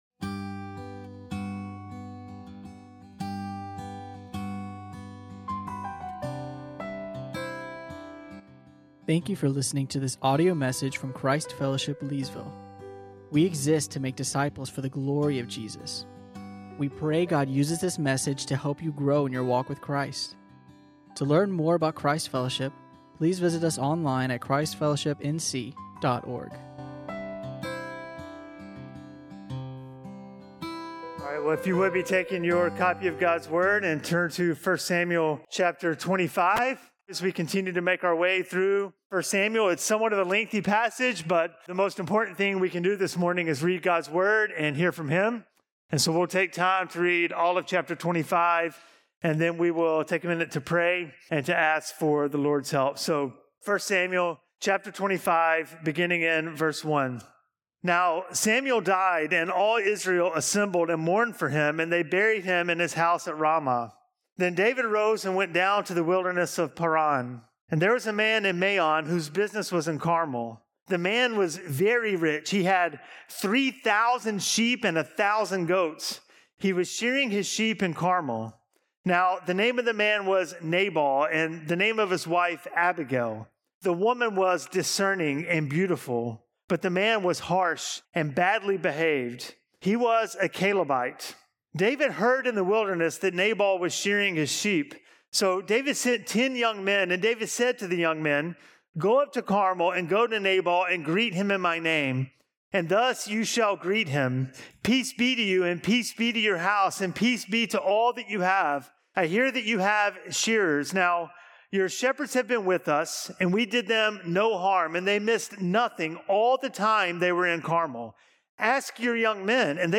teaches on 1 Samuel 25.